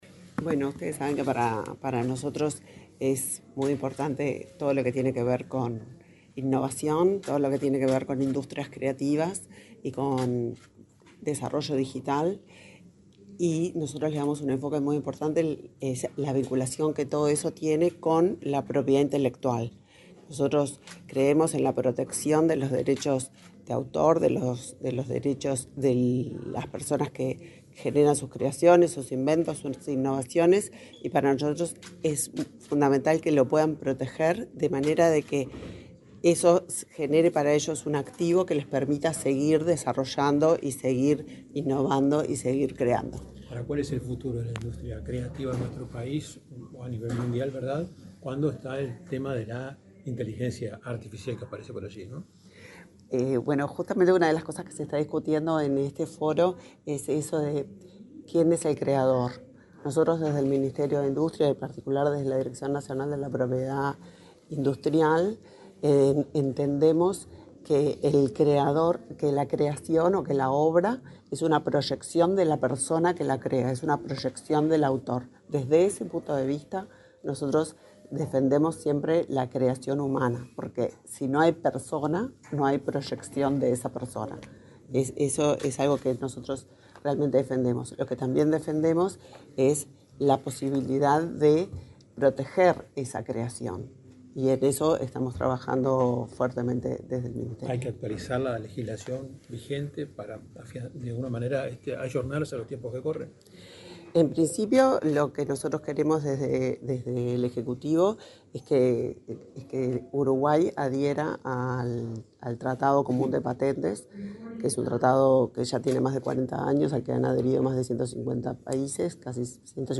Declaraciones de la ministra de Industria, Elisa Facio
Luego, dialogó con la prensa.